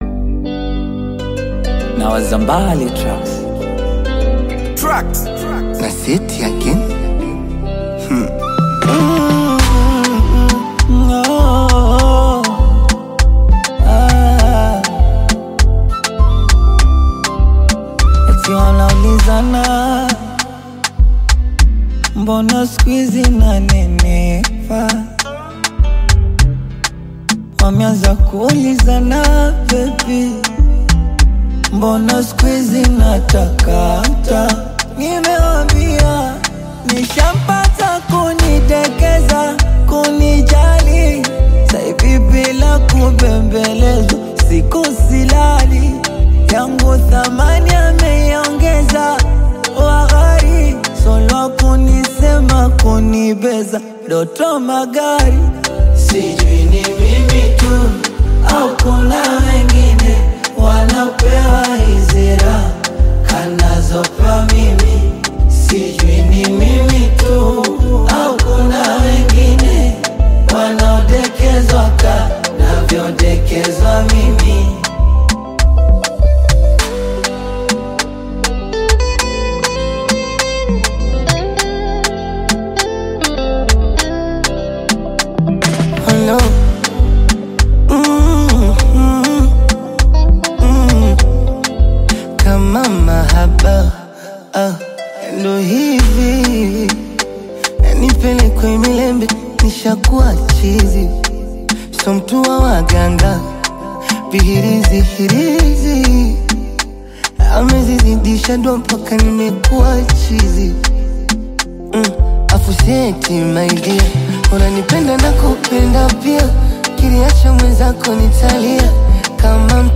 rhythmic percussion